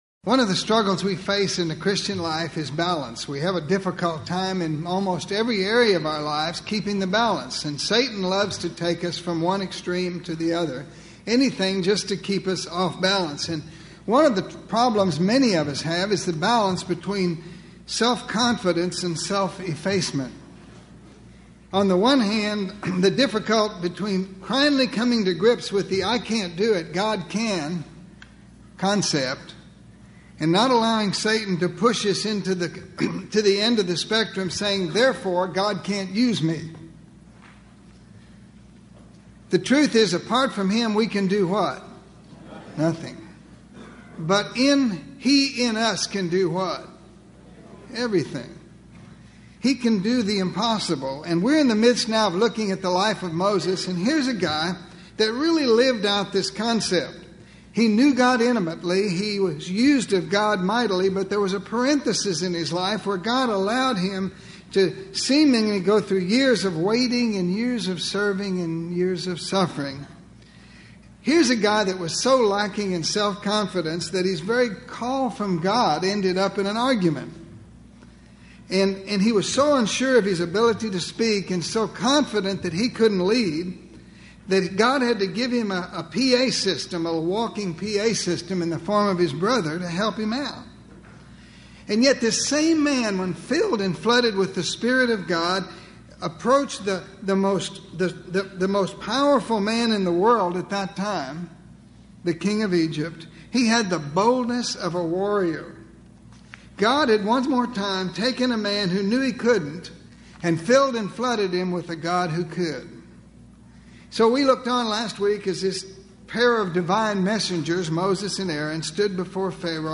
In this lesson, we will look at the last of the plagues placed upon Egypt.